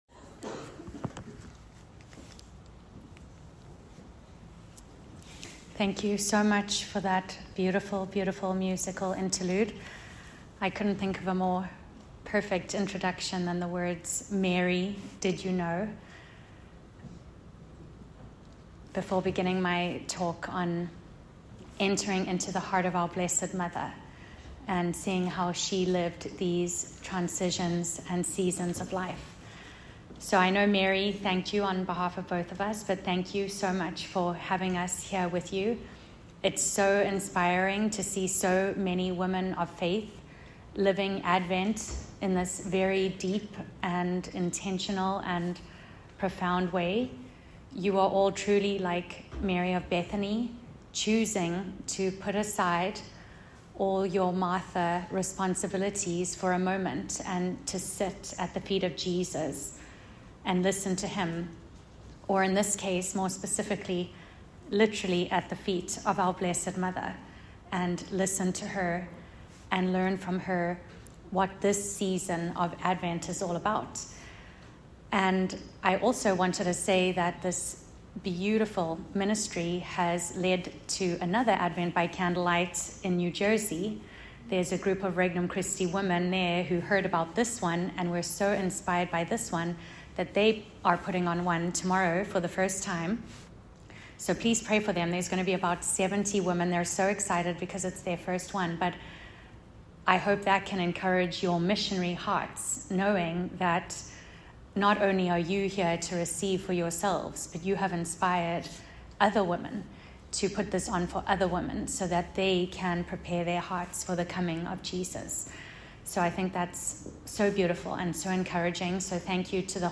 Speaks at Advent by Candlelight
” during Advent by Candlelight at St. Micheals, Greenwich CT.